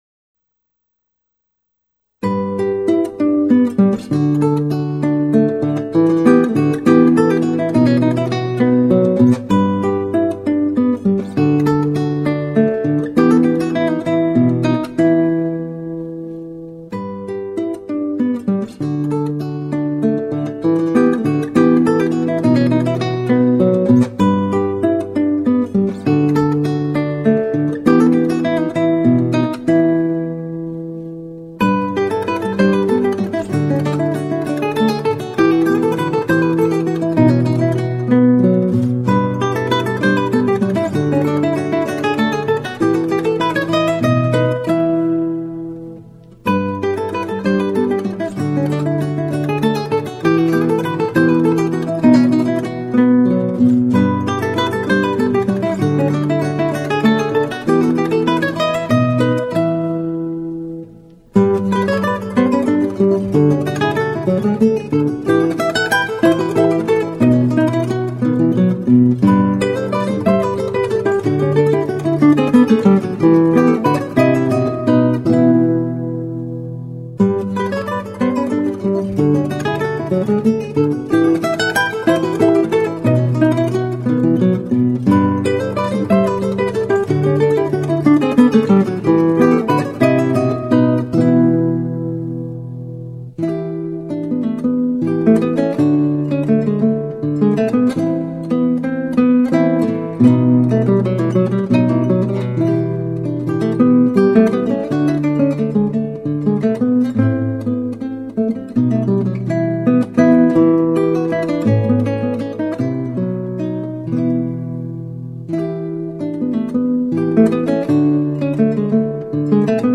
0145-吉他名曲迪芬仁克查.mp3